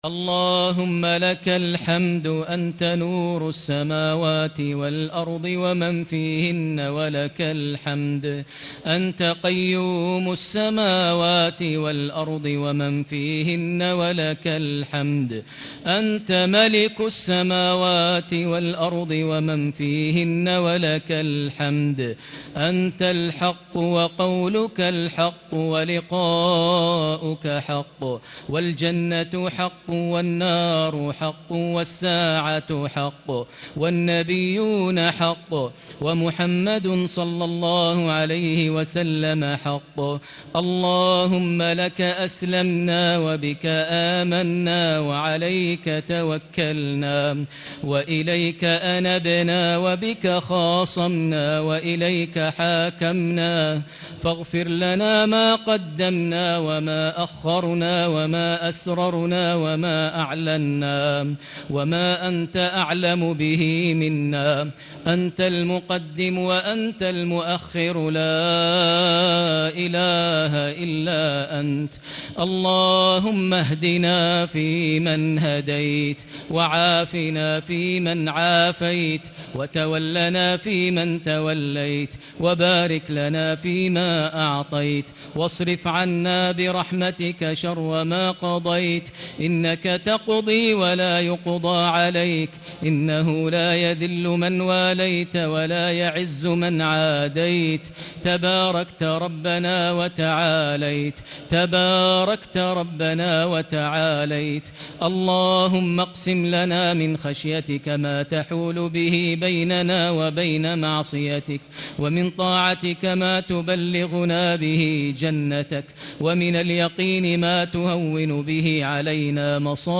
دعاء القنوت ليلة 11 رمضان 1440هـ | Dua for the night of 11 Ramadan 1440H > تراويح الحرم المكي عام 1440 🕋 > التراويح - تلاوات الحرمين